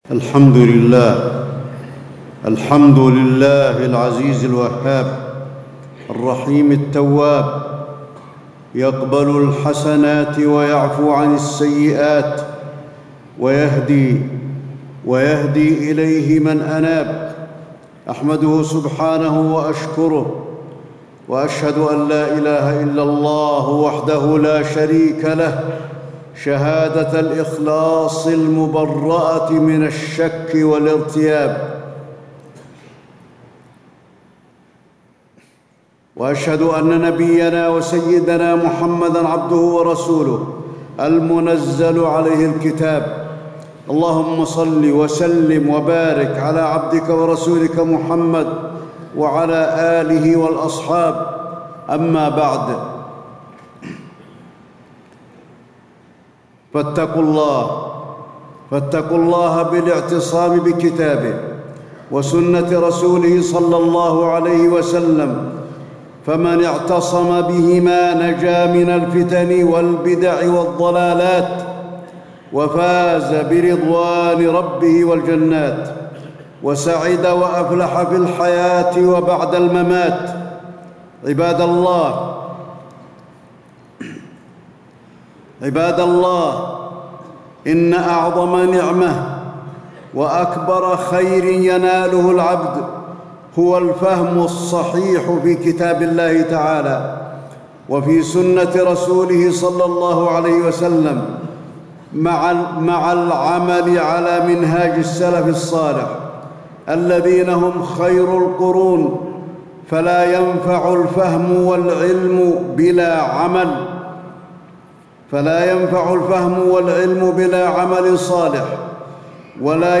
تاريخ النشر ٦ ذو القعدة ١٤٣٦ هـ المكان: المسجد النبوي الشيخ: فضيلة الشيخ د. علي بن عبدالرحمن الحذيفي فضيلة الشيخ د. علي بن عبدالرحمن الحذيفي اتباع الكتاب والسنة لا ابتداع الخوارج